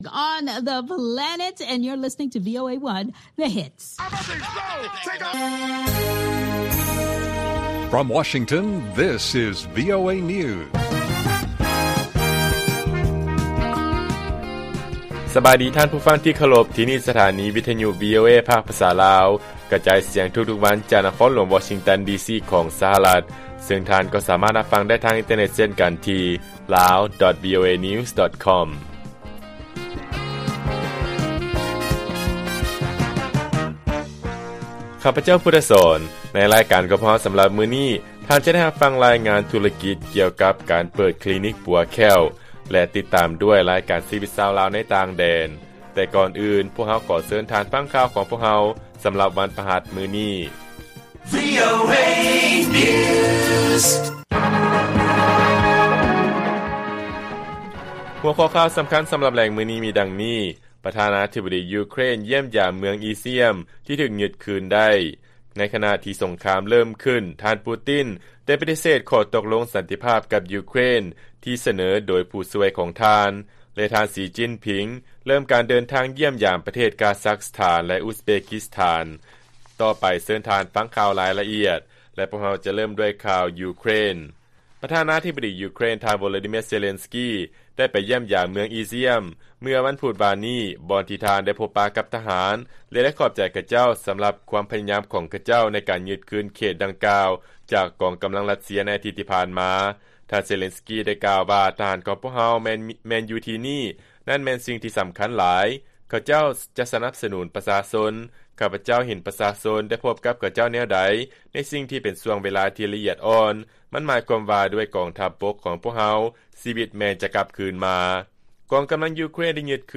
ລາຍການກະຈາຍສຽງຂອງວີໂອເອ ລາວ: ປະທານາທິບໍດີ ຢູເຄຣນ ຢ້ຽມຢາມເມືອງ ອີຊຽມ ທີ່ຖືກຍຶດໄດ້ຄືນ